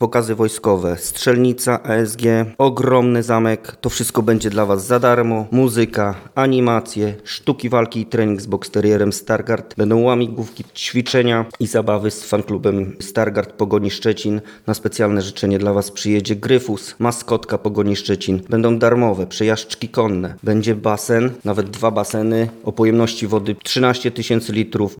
– wyjaśnia sołtys Żarowa Krzysztof Cęcak.